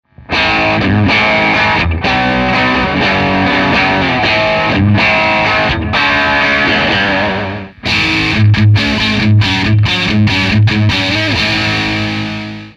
1台でオーバードライブとディストーション、そして両者のコンビネーションを実現！強力な歪みです。